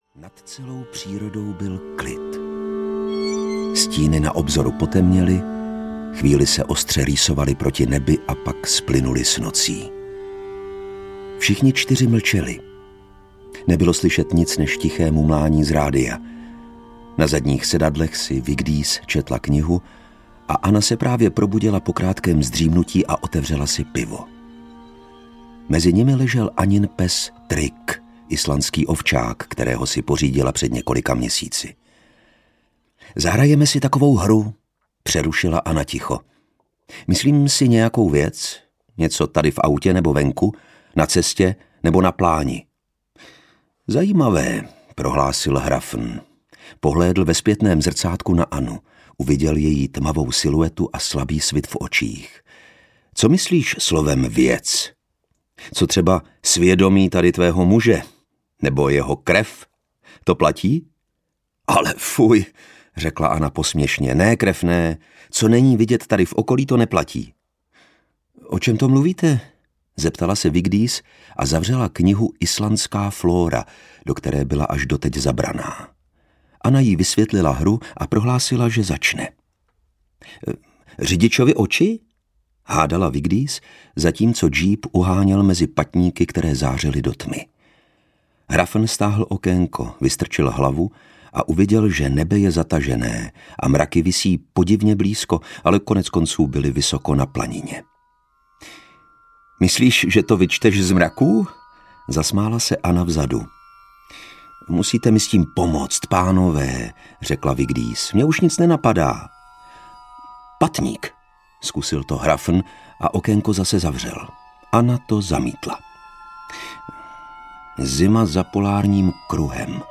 Planina audiokniha
Ukázka z knihy
• InterpretLukáš Hlavica